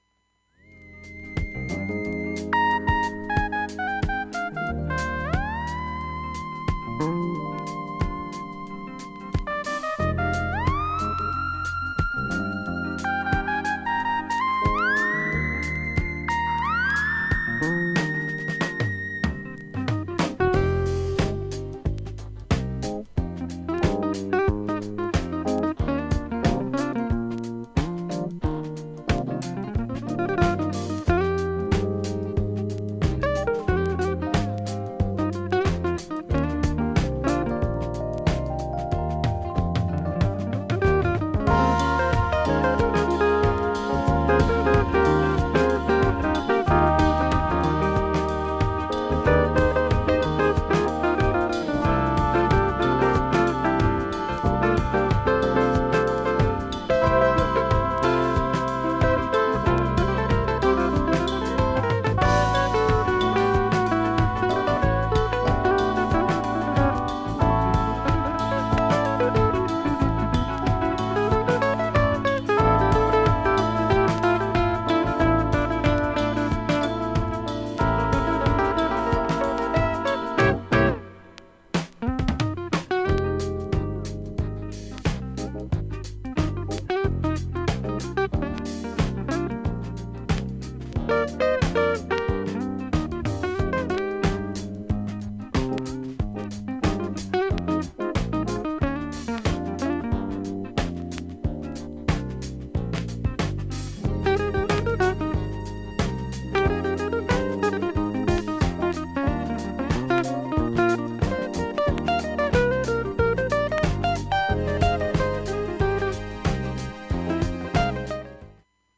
スレ1本で1分半ほどプツ出ますが大半がごくわずかです。
洗い物などしているときずかないレベルです。
後半にプツ音少し聴こえてきますが、
現物の試聴（上記）できます。音質目安にどうぞ
ほかきれいで問題無し音質良好全曲試聴済み
ジャズ鍵盤奏者の77年作